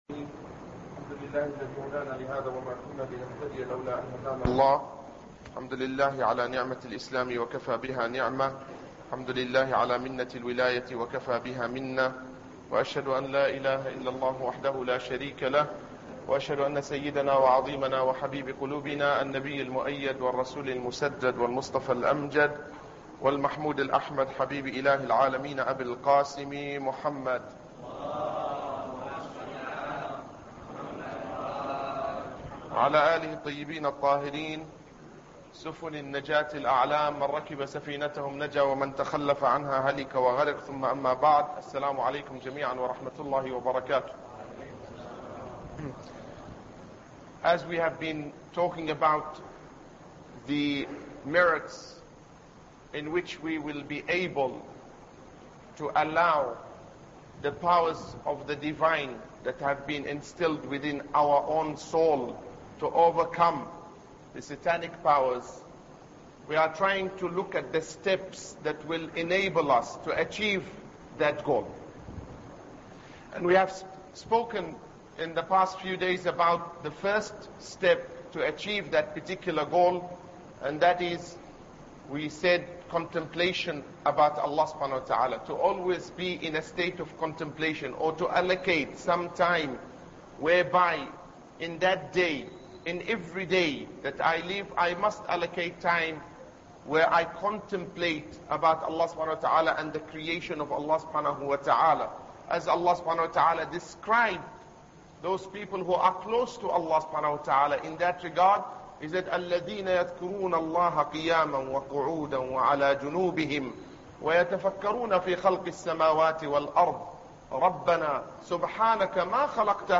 Ramadan Lecture 5